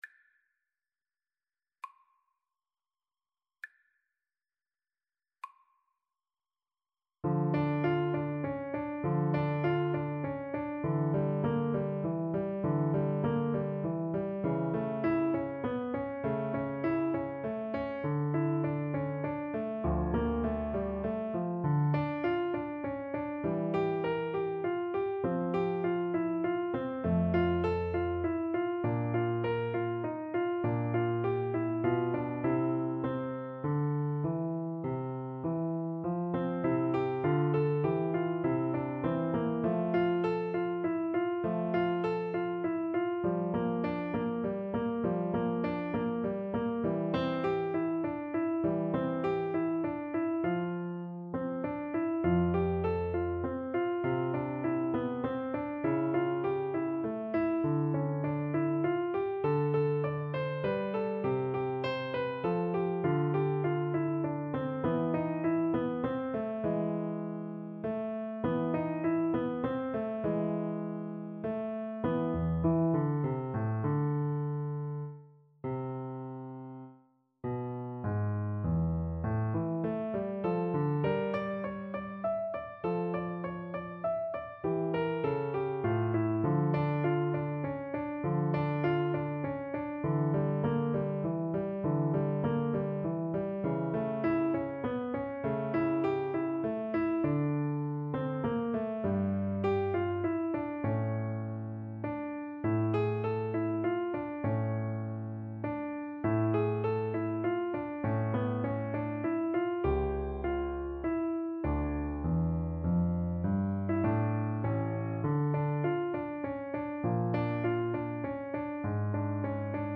6/8 (View more 6/8 Music)
Siciliano =100
Classical (View more Classical French Horn Music)